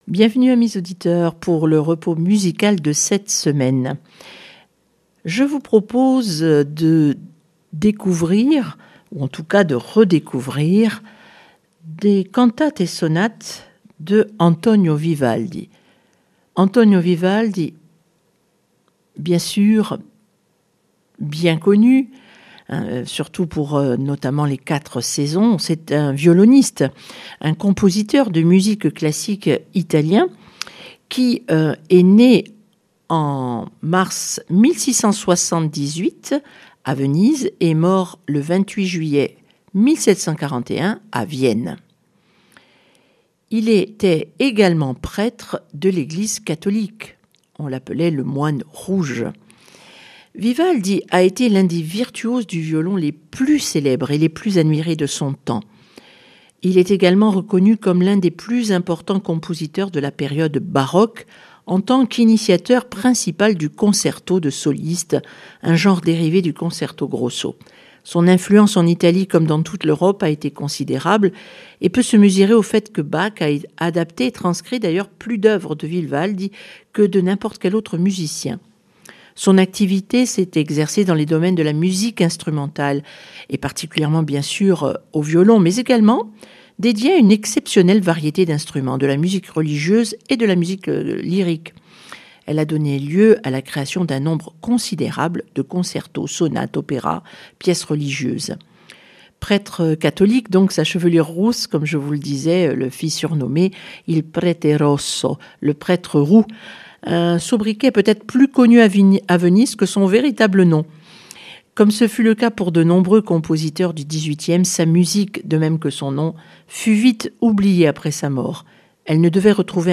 cantate et sonate Vivaldi